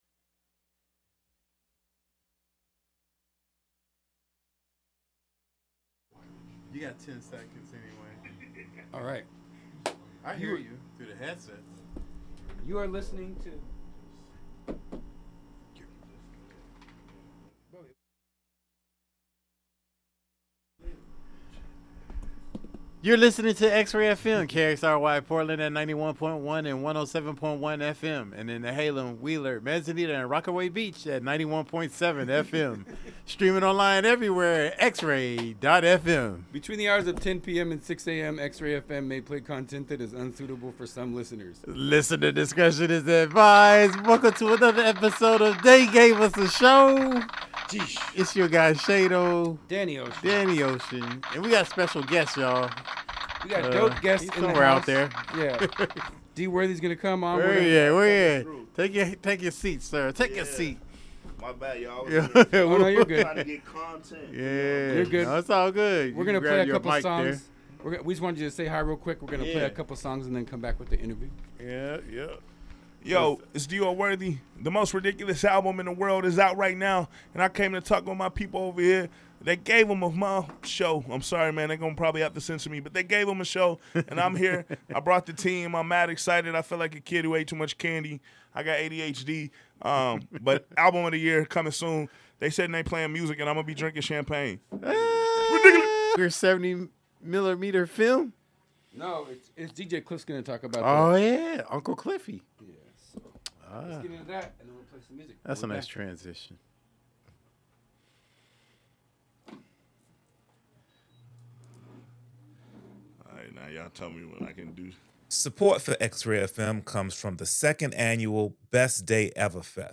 New and throwbacks, from Portland and beyond. Plus, catch interviews from talented artists.